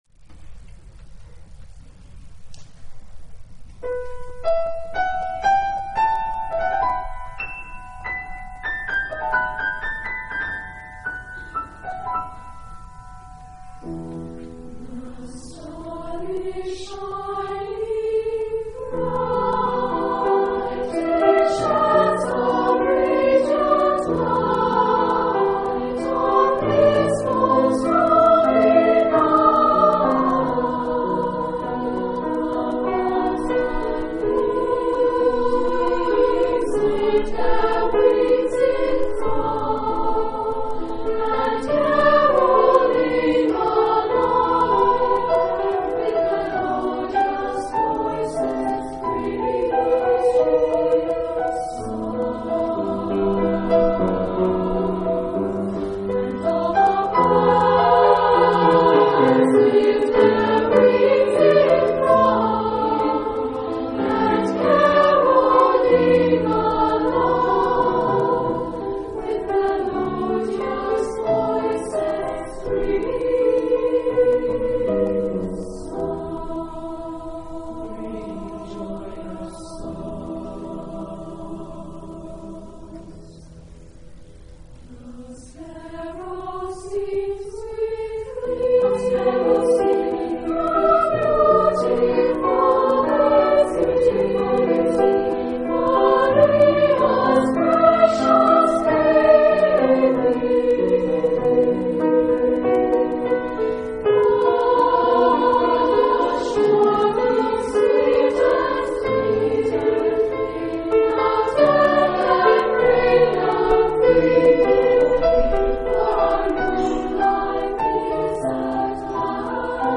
Genre-Stil-Form: geistlich ; Carol ; Villancico ; Wiegenlied ; Hymnus (geistlich)
Charakter des Stückes: sanft ; fliessend ; ruhig
Chorgattung: SA  (2 Kinderchor ODER Frauenchor Stimmen )
Solisten: Soprano (1)  (1 Solist(en))
Instrumente: Klavier (1)
Tonart(en): e-moll